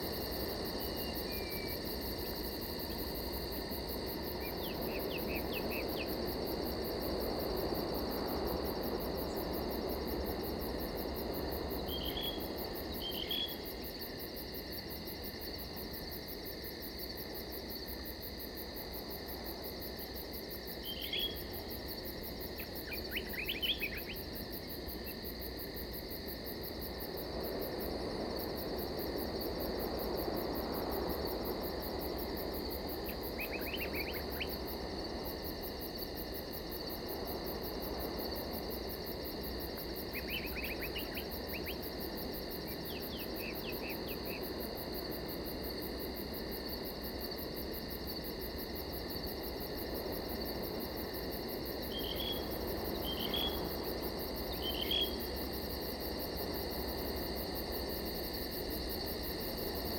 Forest Night.ogg